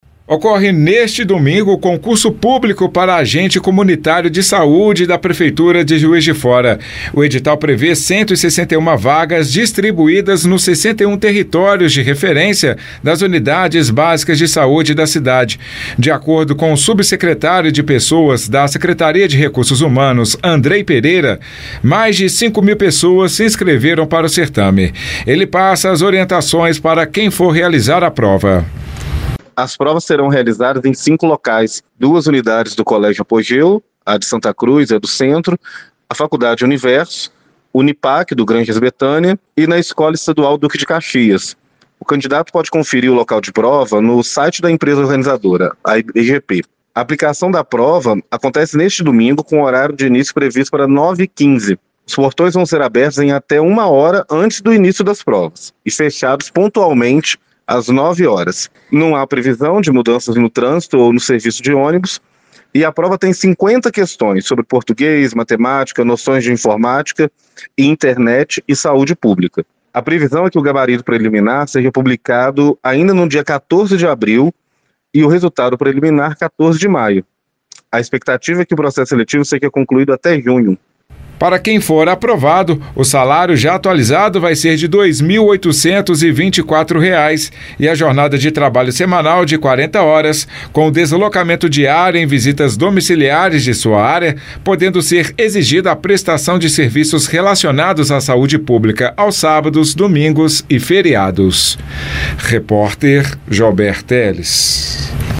Confira as orientações para os participantes na reportagem